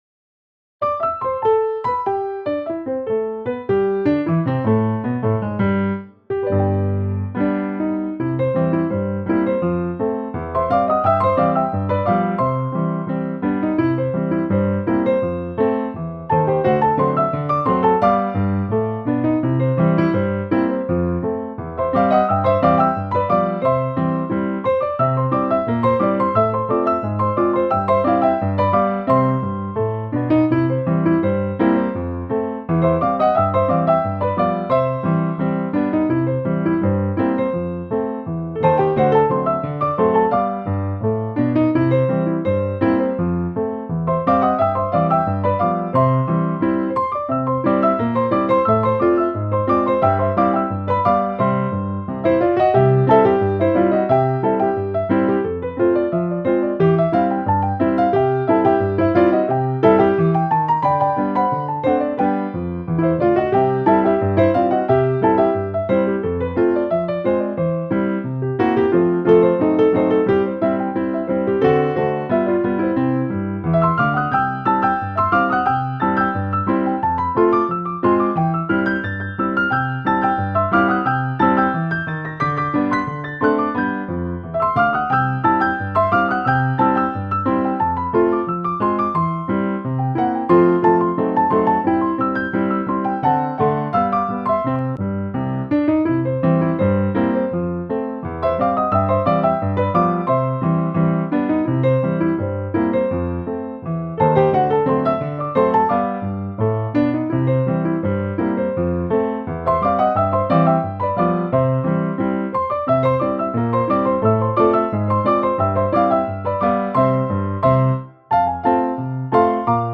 Aufnahme von Scott Joplins Klavierstück »The Entertainer« (gemeinfrei/Wikimedia Commons). Scott Joplin hat es auf einer Piano Roll selbst eingespielt. Dabei handelt es sich um eine Lochkarte, die von Klaviermusikautomaten abgespielt werden kann.
• The Entertainer: Aufnahme der Piano Roll (MP3) via Wikimedia Commons (gemeinfrei).
The_Entertainer__by_Scott_Joplin_1902_Ragtime_piano.opus_.mp3